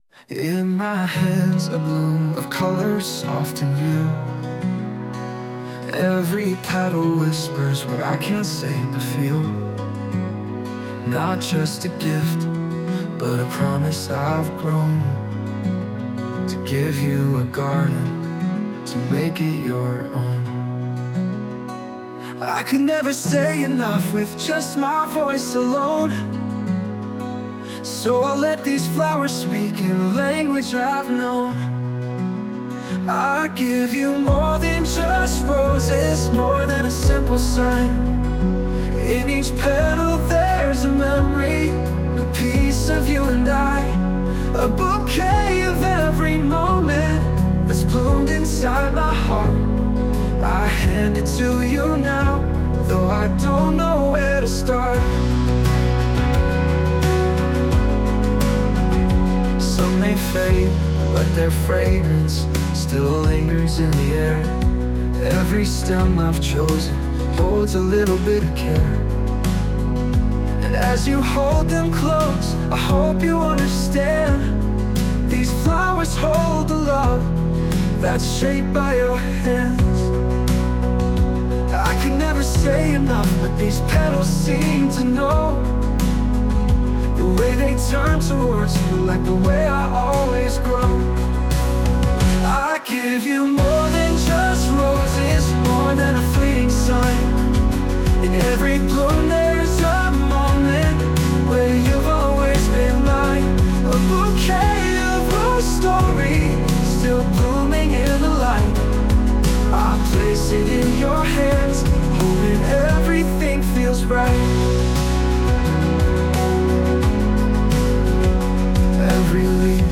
洋楽男性ボーカル著作権フリーBGM ボーカル
著作権フリーオリジナルBGMです。
男性ボーカル（洋楽・英語）曲です。
大切な人に花束を贈る時の心情を描いた、心温まる曲です。